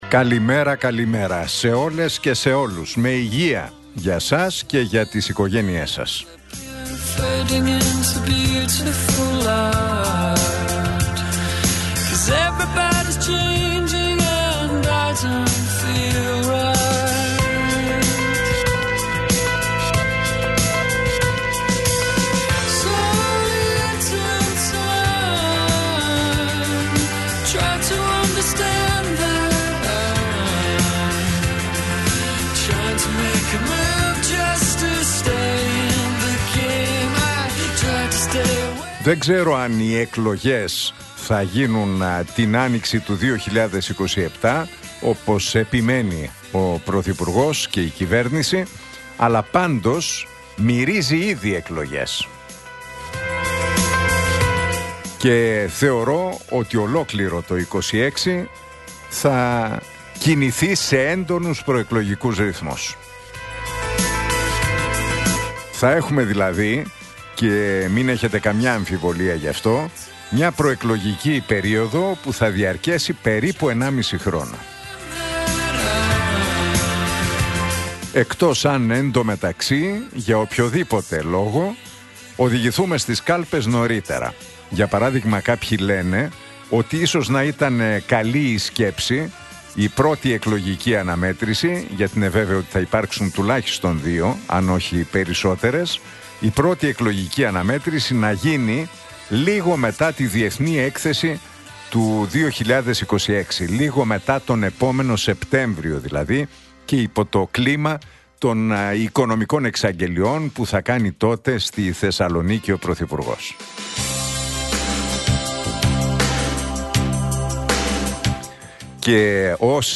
Ακούστε το σχόλιο του Νίκου Χατζηνικολάου στον ραδιοφωνικό σταθμό Realfm 97,8, την Τρίτη 18 Νοεμβρίου 2025.